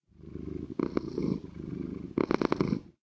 purr2.ogg